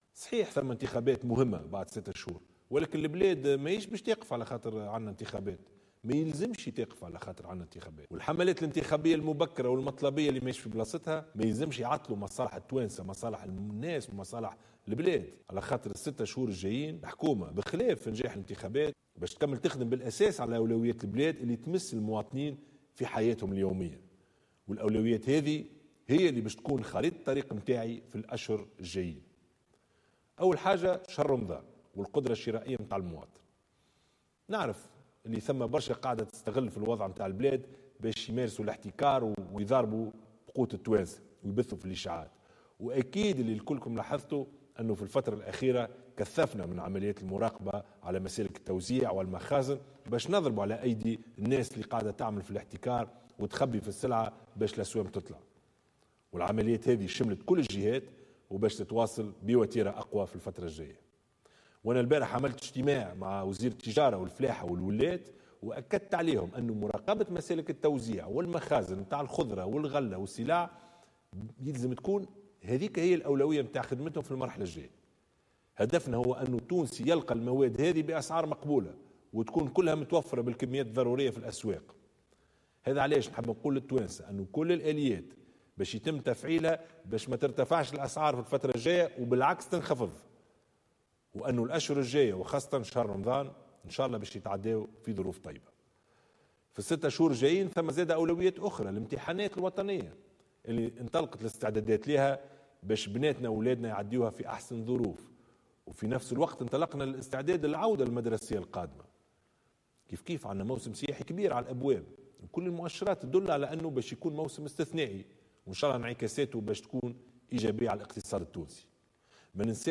وأضاف الشاهد خلال كلمة ألقاها على قناة الوطنية الأولى، أن الأسعار لن ترتفع خلال شهر رمضان وإنما ستنخفض، وأن الحكومة ستفعّل كل الآليات لذلك.